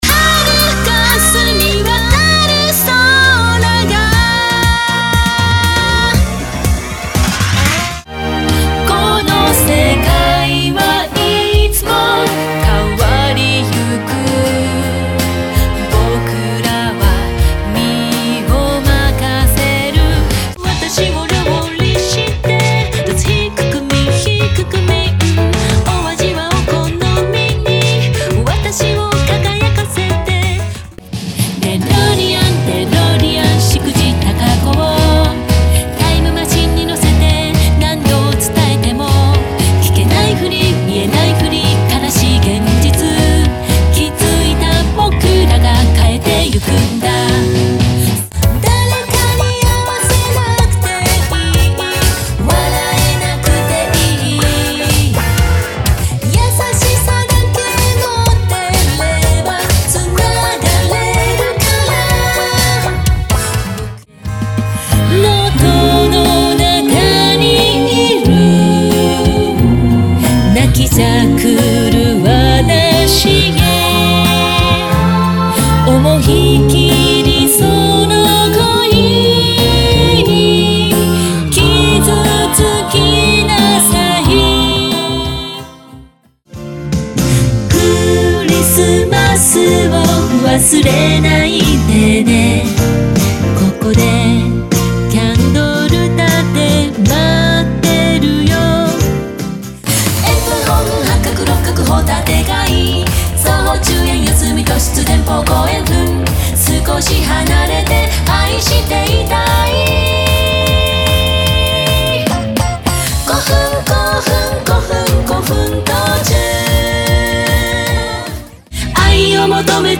Guitar
Sax